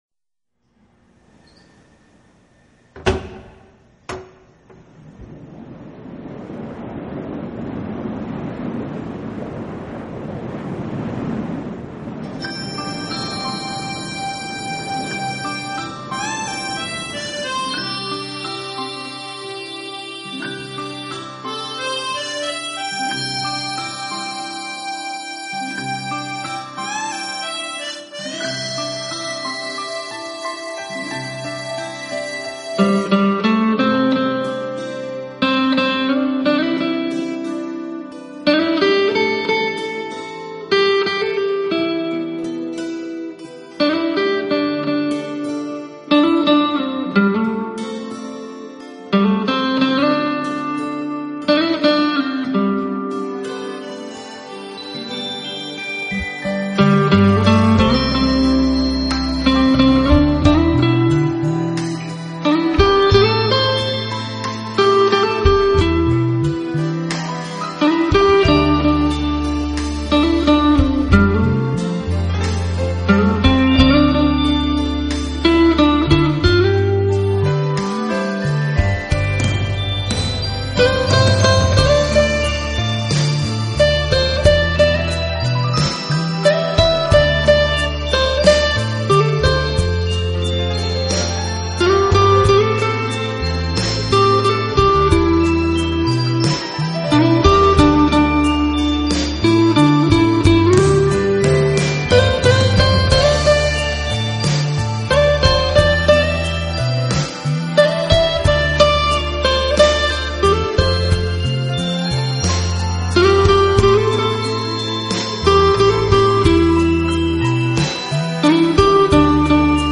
【吉他专辑】
聆听新世纪梦幻般的清脆吉它声，浪漫吉它风情演绎，让你畅游梦幻极乐音乐，唯美